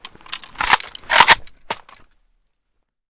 akm_unjam.ogg